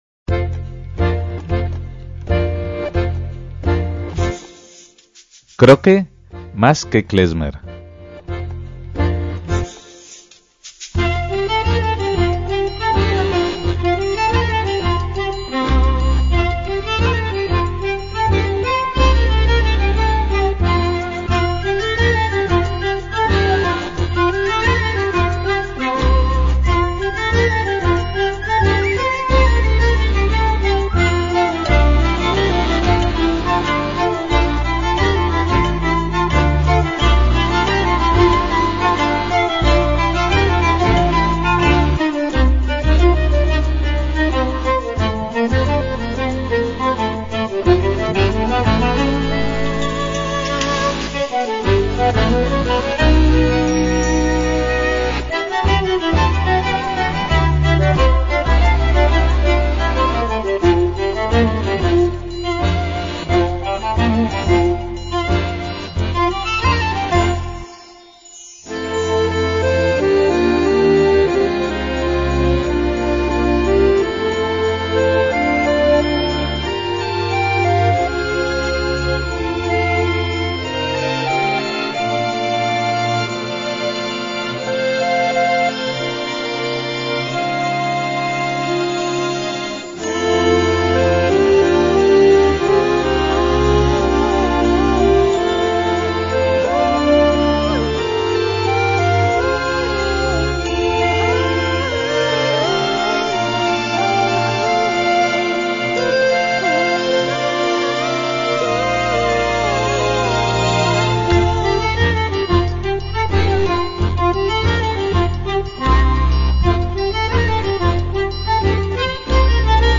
una banda polaca formada en 1992
contrabajo
viola
acordeón
piezas inspradas en otras culturas y estilos